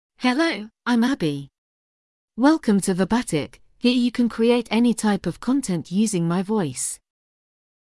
FemaleEnglish (United Kingdom)
Abbi is a female AI voice for English (United Kingdom).
Voice sample
Female
Abbi delivers clear pronunciation with authentic United Kingdom English intonation, making your content sound professionally produced.